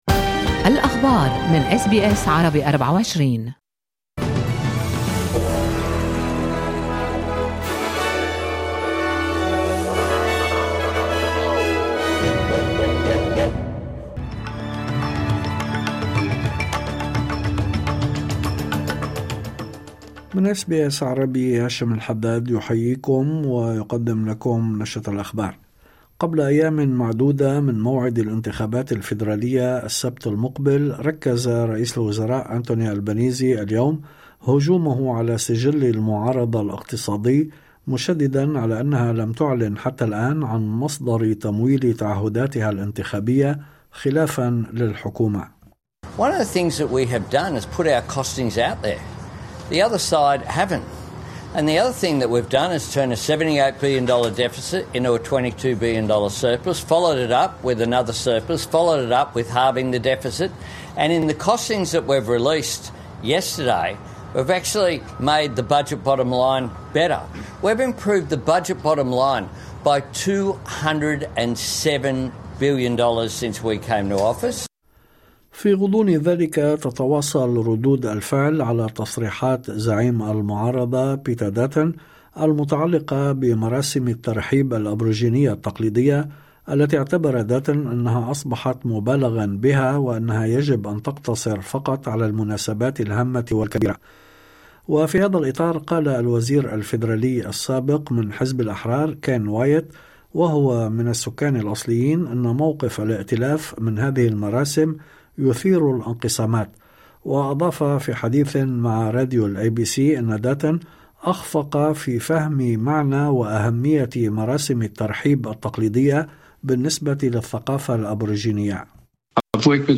نشرة أخبار الظهيرة 29/04/2025